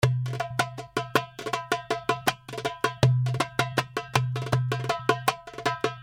Waheda Darbuka 80 BPM
The darbuka you are hearing and downloading is in stereo mode, that means that The darbuka was recorded twice.(beat in the left speaker).
This package contains real darbuka loops in waheda style, playing at 80 bpm.
The darbuka was recorded with vintage neumann u87 in a dry room by a professional Darbuka player.
The darbuka is in mix mode( no mastering,no over compressing ). There is only light and perfect analog EQ and light compression, giving you the The opportunity to shape the loops in the sound you like in your song.